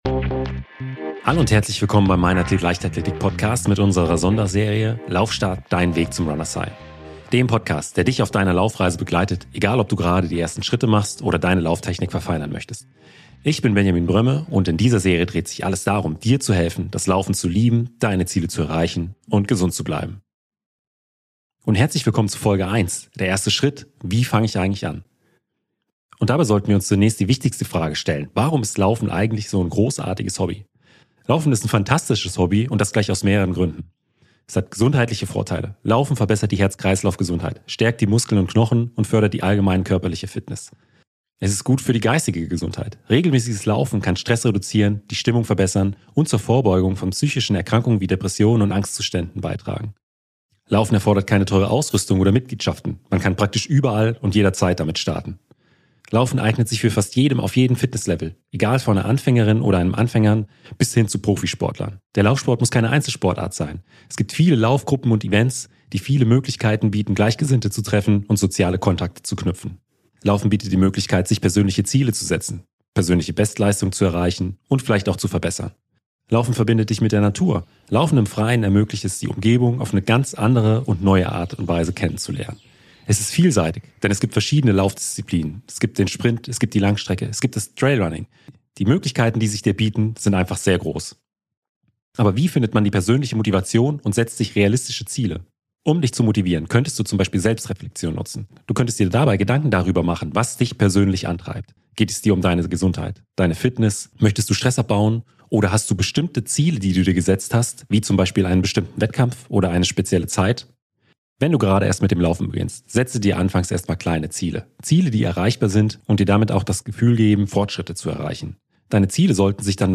Im Mainathlet Leichtathletik Podcast geht es rund um die Leichtathletik und die Athleten aus allen Disziplinen. Ich werde regelmäßig aktive und ehemalige deutsche Leichtathleten und Leichtathletinnen interviewen. Außerdem lasse ich Trainer und Unterstützer zu Wort kommen.